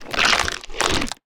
minecraft / sounds / mob / camel / eat1.ogg
eat1.ogg